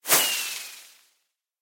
launch1.ogg